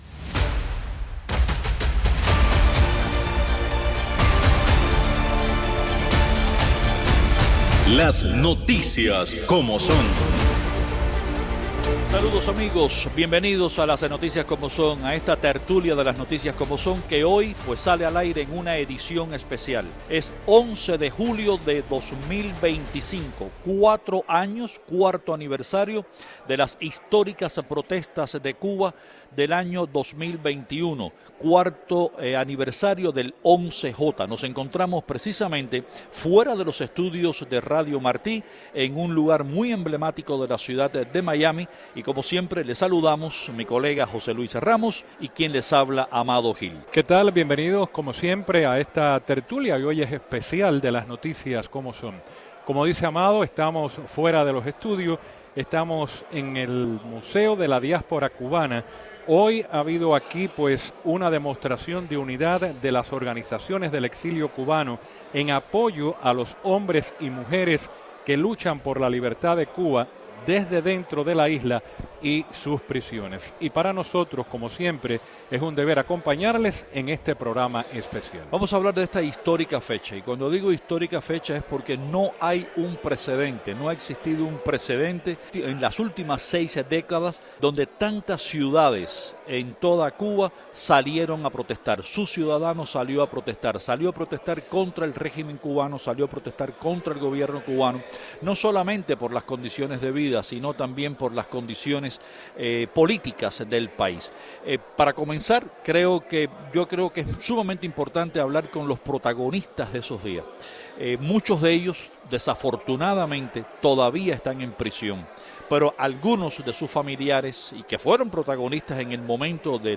Martí Noticias transmitió este viernes desde el Museo Americano de la Diáspora Cubana durante un evento para recordar las masivas manifestaciones del 11 de julio del 2021.